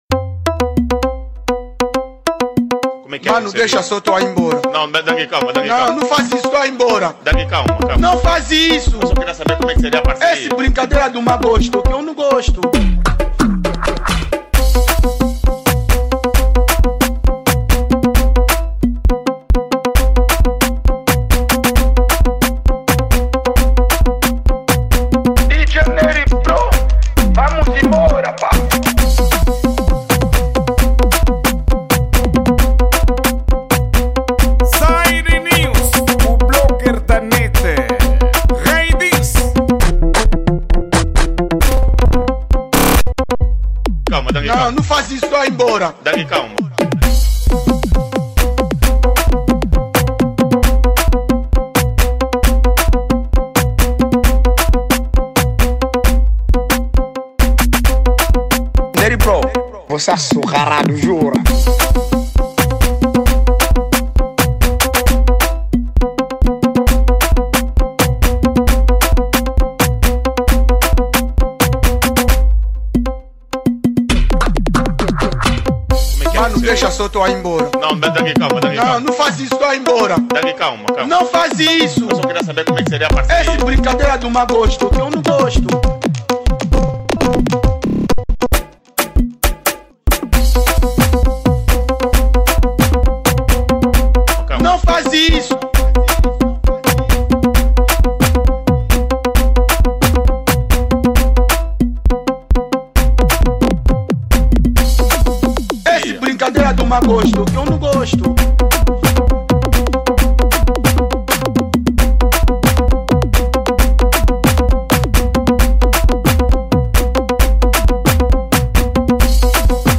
Gênero:Afro House